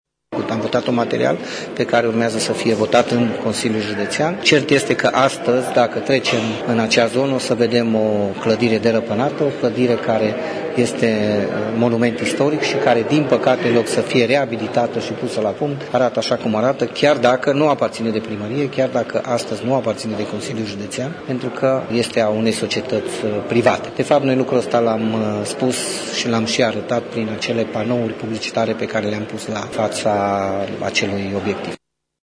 Următorul pas este ca şi Consiliul Judeţean să adopte o hotărâre asemănătoare, explică viceprimarul Claudiu Maior: